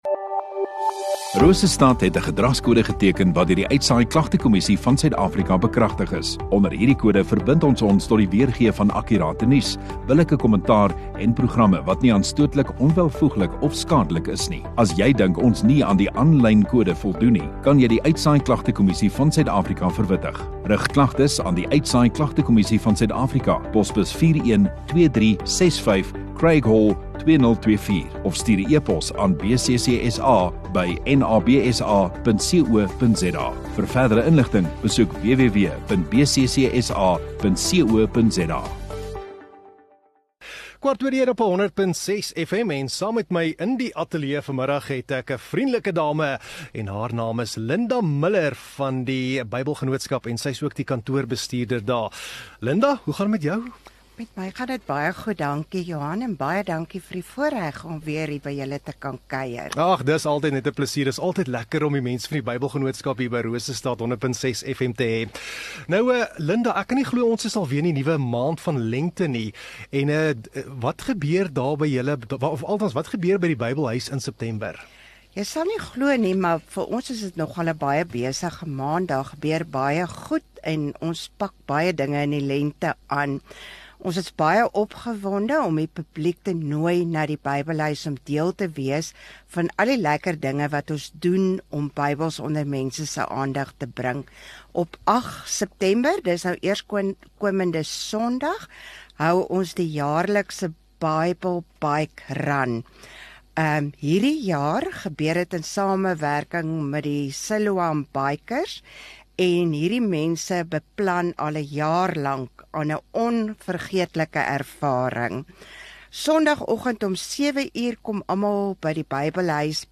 Radio Rosestad View Promo Continue Radio Rosestad Install Gemeenskap Onderhoude 3 Sep Bybelgenootskap